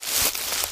High Quality Footsteps
STEPS Bush, Walk 13.wav